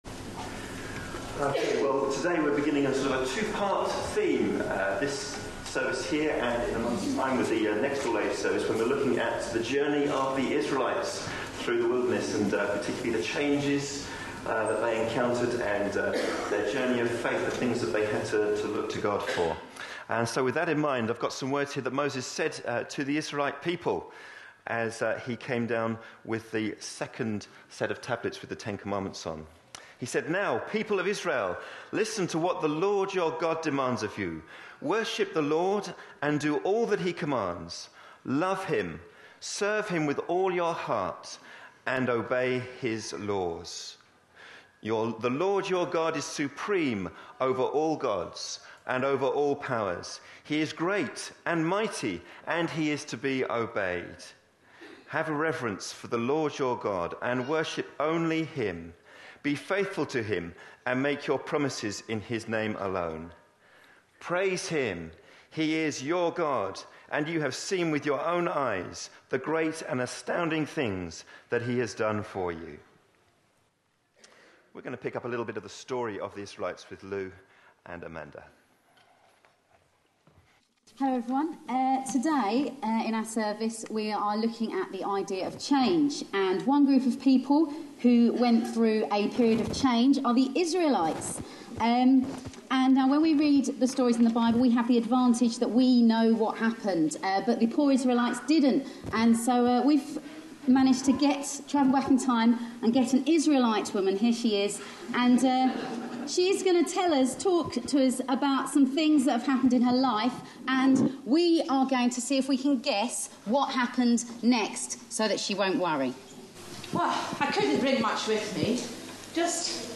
A sermon preached on 19th January, 2014.